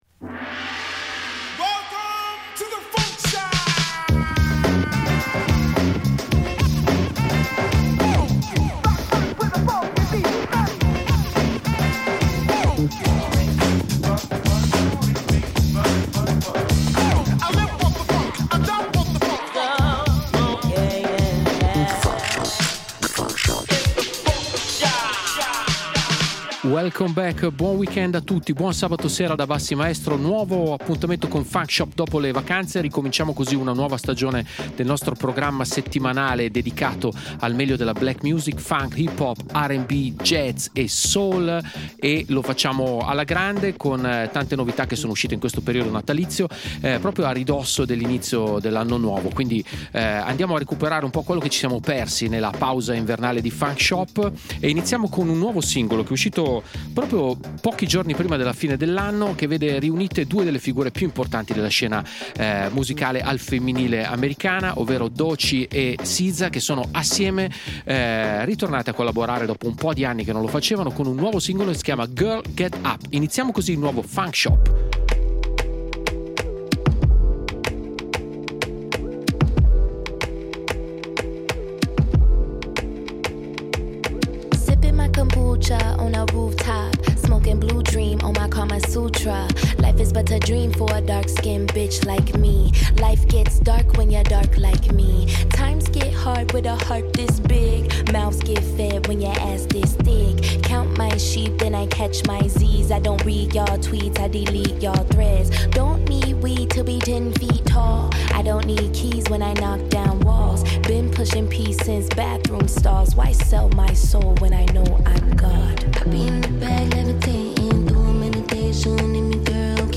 BLACK RAP / HIP-HOP